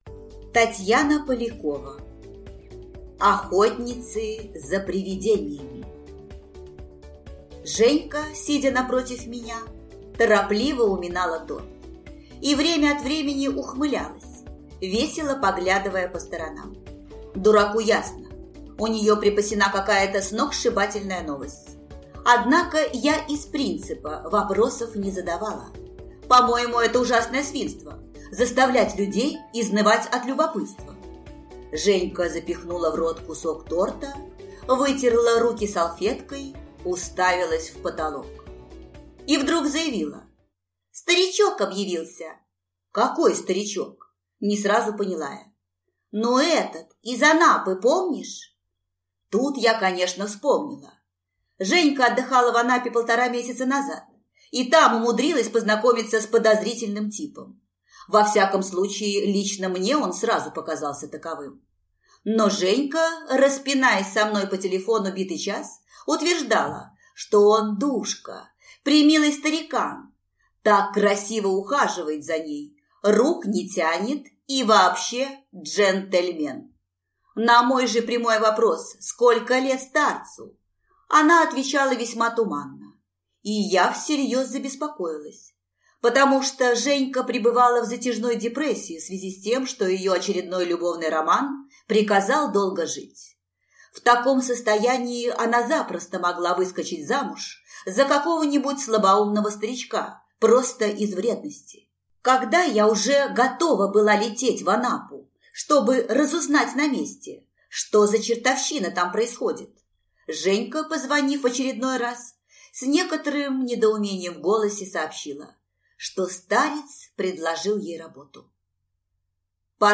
Аудиокнига Охотницы за привидениями | Библиотека аудиокниг